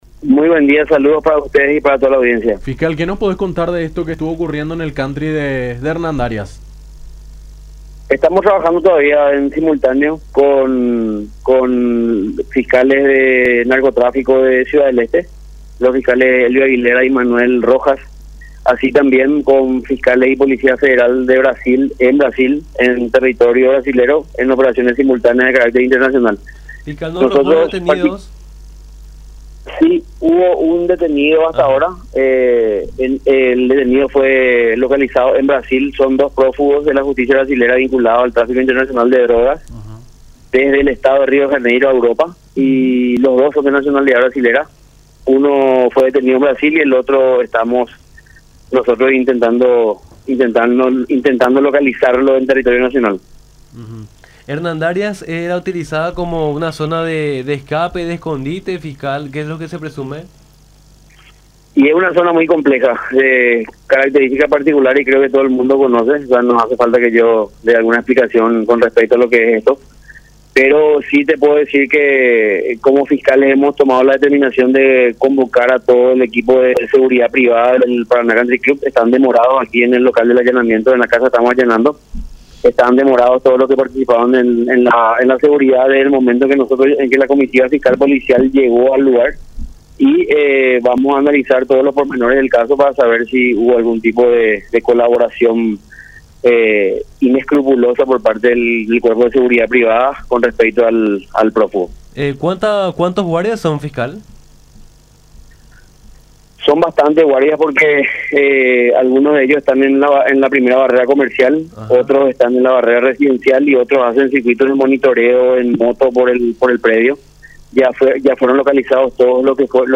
“Hemos tomado la decisión de demorar a todo el personal de seguridad privada de este local (…) Es una causa que se lleva en Brasil, en Río de Janeiro y es por eso que el operativo es conjunto”, dijo el fiscal de Asuntos Internacionales, Manuel Doldán, en diálogo con Nuestra Mañana por La Unión.